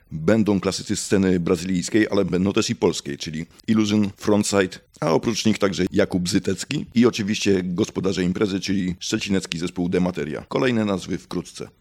– informuje nasz reporter